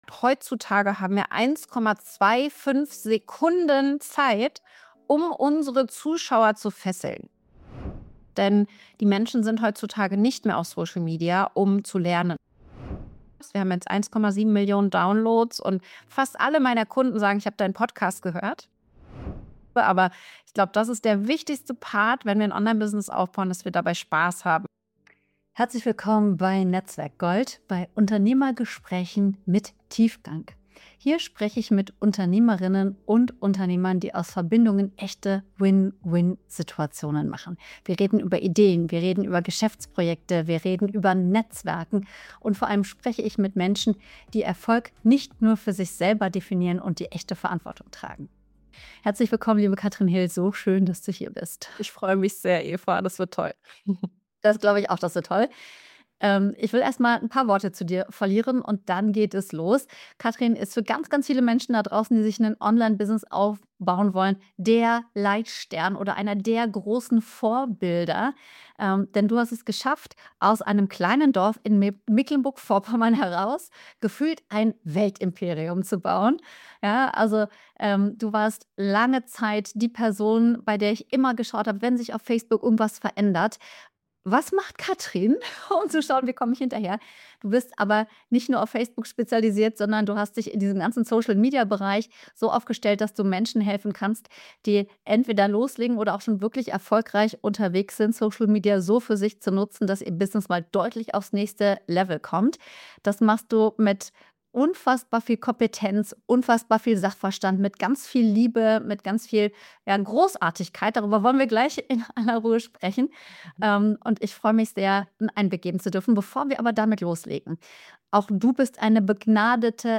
Willkommen beim NetzwerkGold Podcast – Unternehmergespräche mit Tiefgang.